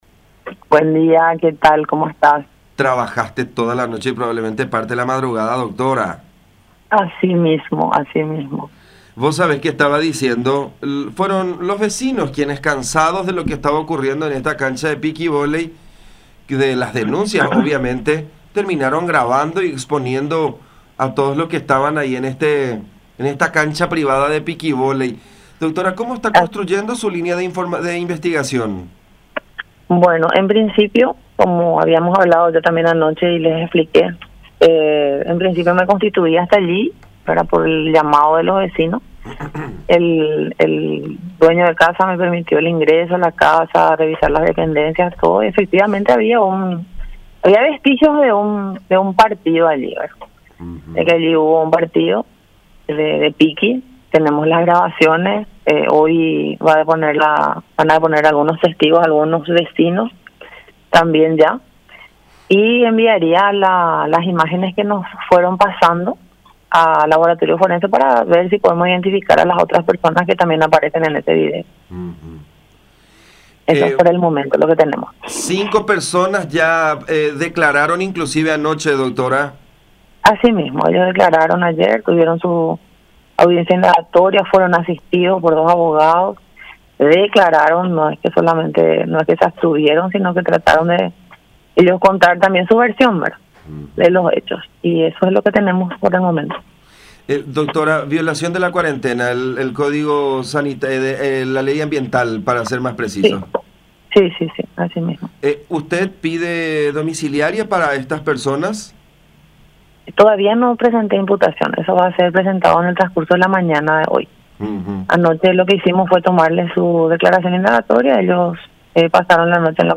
Laura Romero, fiscal del caso, expuso que en el transcurso de este jueves se presentará el acta de imputación ante el juzgado y es casi seguro que reciban prisión domiciliaria.